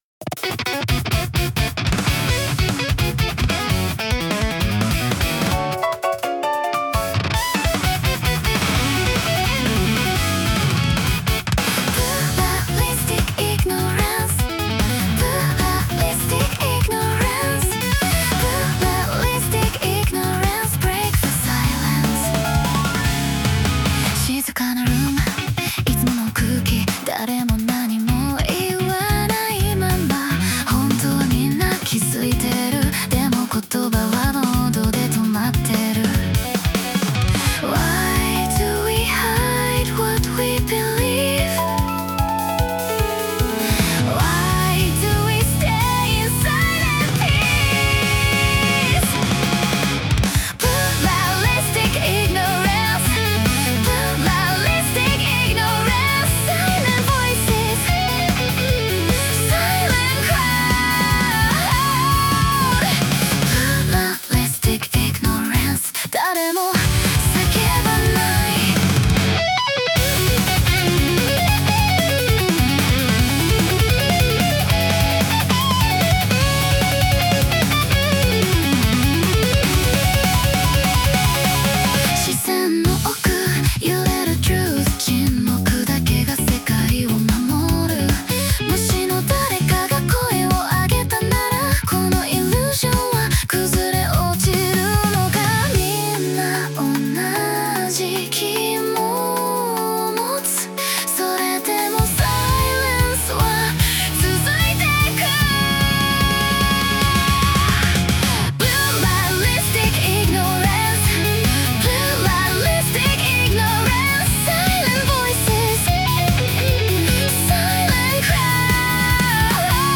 イメージ：邦ロック,J-ROCK,女性ボーカル,シューゲイザー